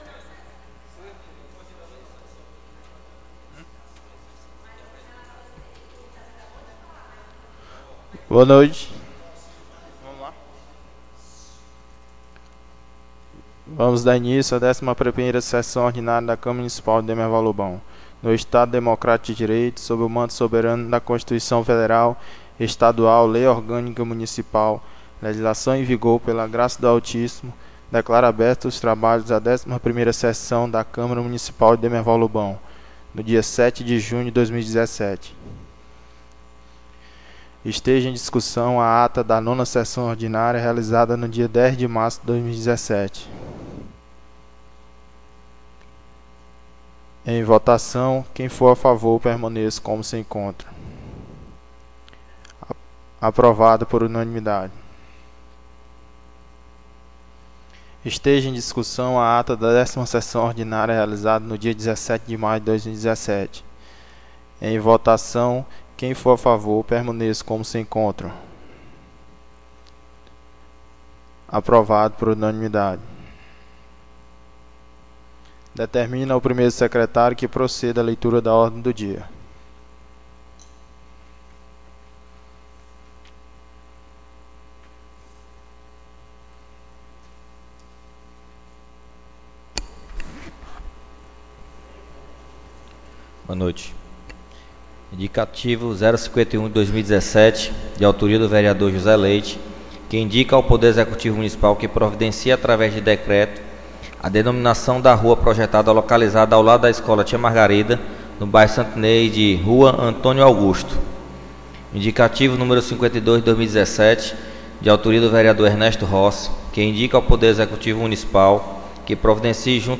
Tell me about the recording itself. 11ª SESSÃO ORDINÁRIA 07/06/2017